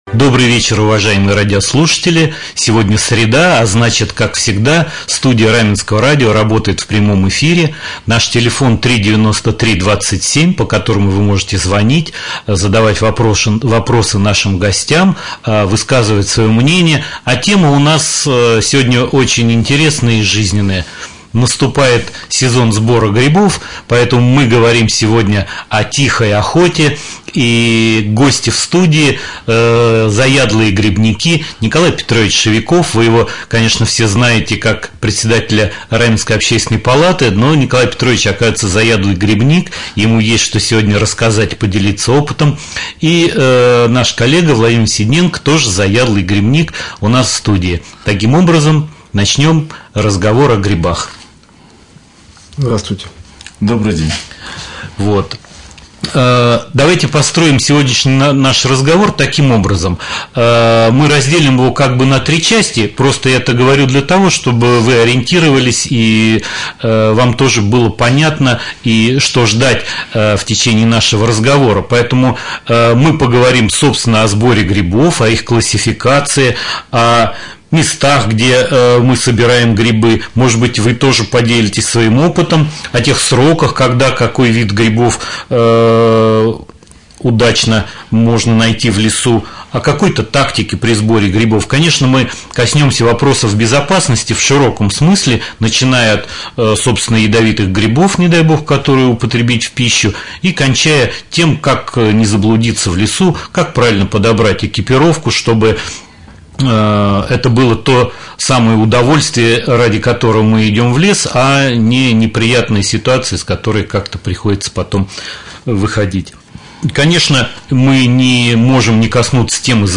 Прямой эфир. Тема эфира:Грибной сезон.